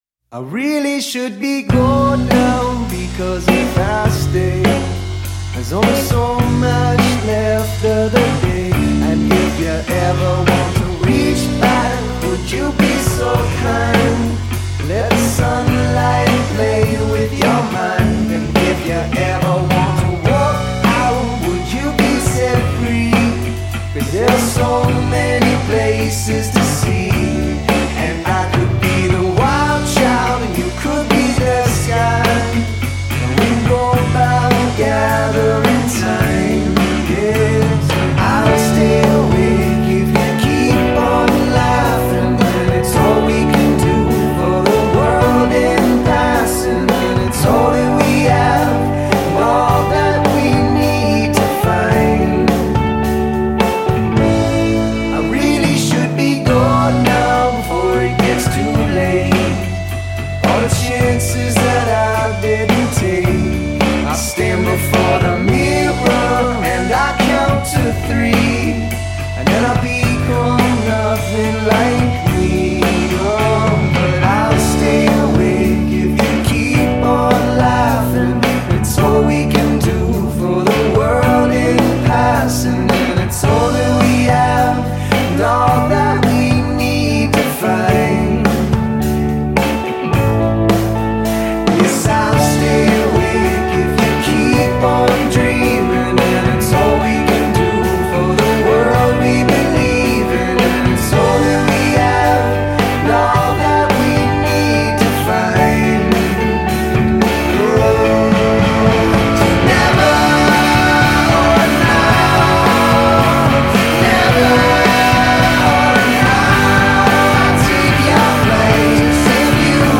On the roots rock trio’s first album in 12 years